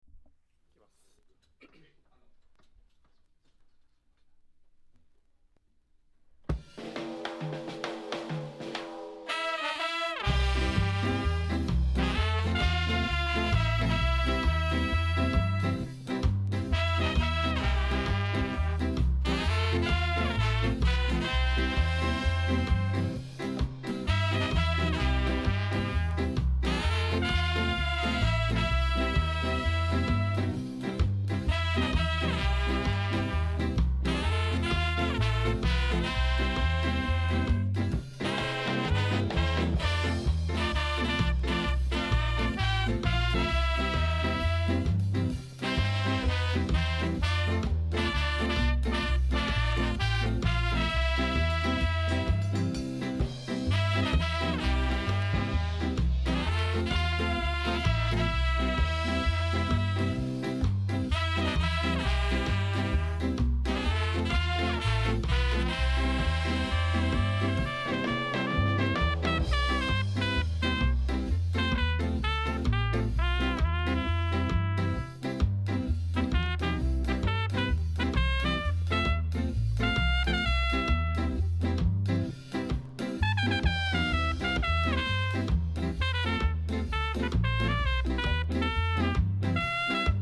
第1弾は、通算5枚目となるオリジナル・ロックステディとカヴァー・スカをカップリング。